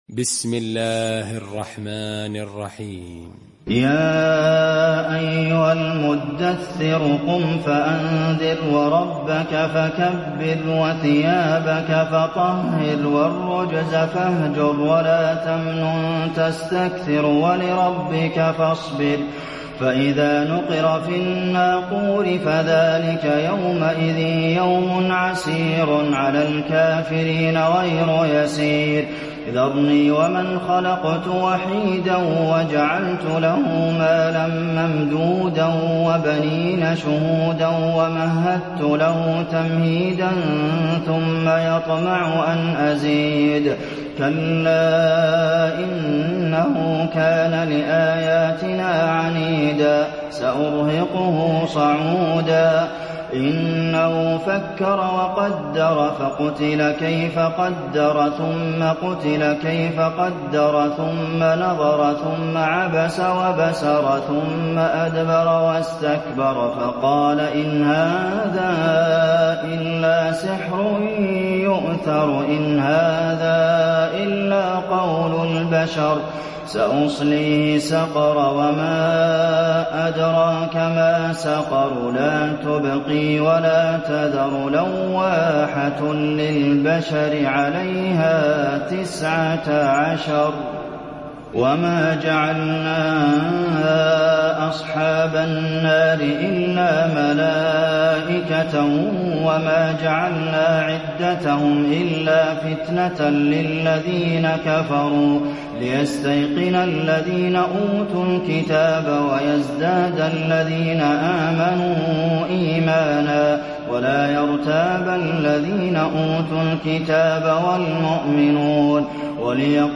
المكان: المسجد النبوي المدثر